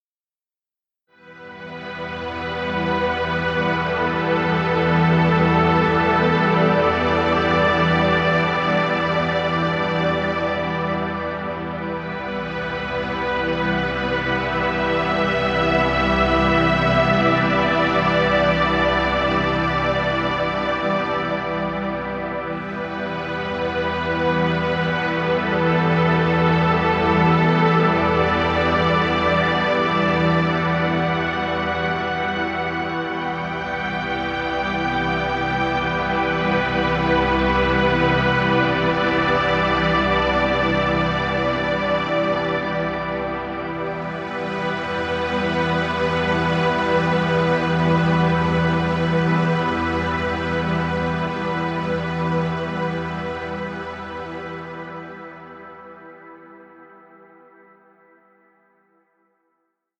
Relaxing music.